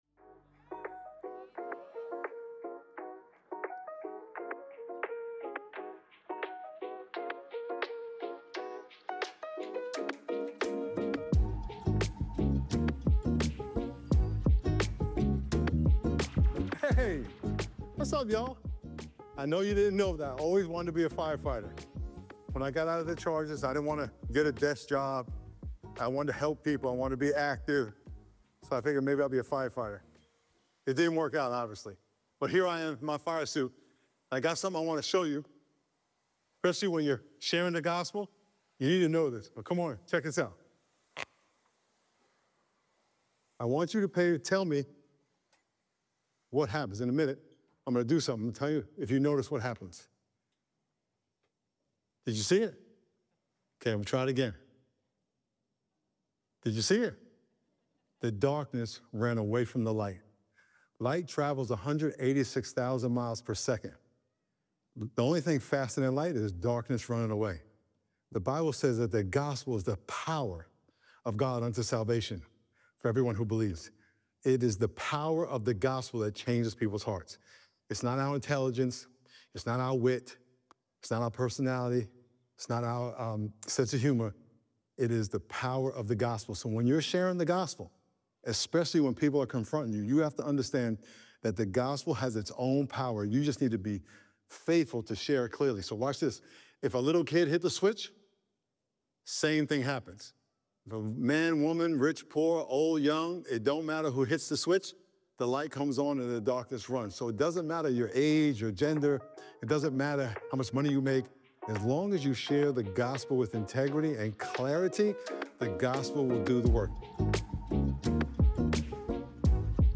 Rock Church offers podcasts of the weekly messages.